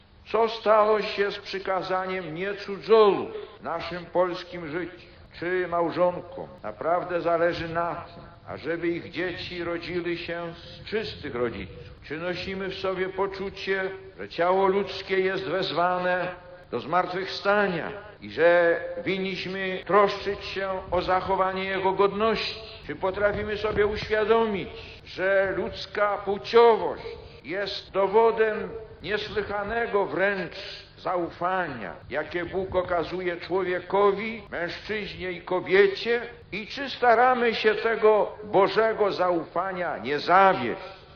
Fragment homilii Jana Pawła II o szóstym przykazaniu: